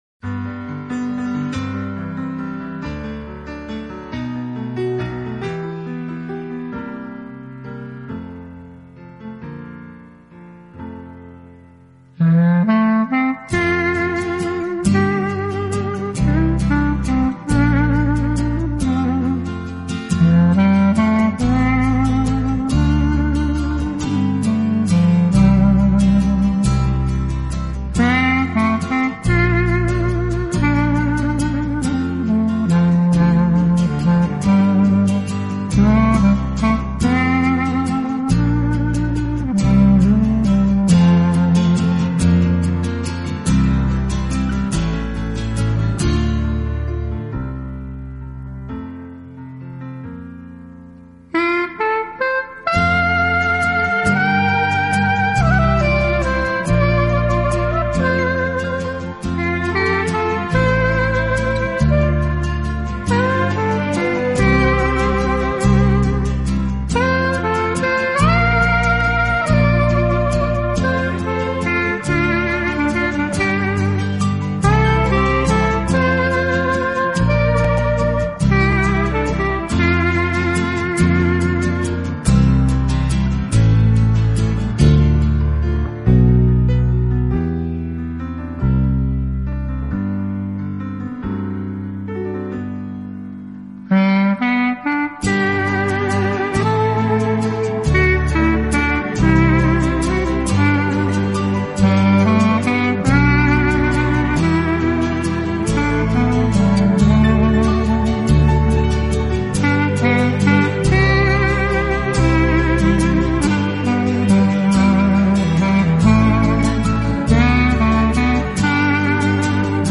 【黑管专辑】
感情的表现及控制的技巧，是最大吸引处，每一粒音的强音、弱音、震音，或有时表现